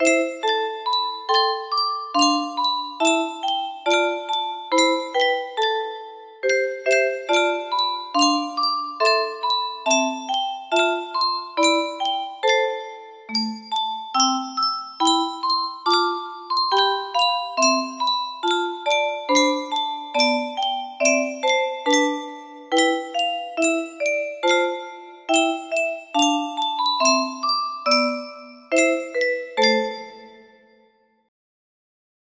Huron Carol music box melody